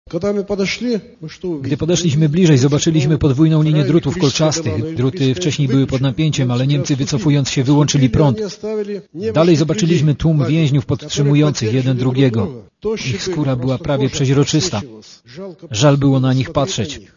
weteran.mp3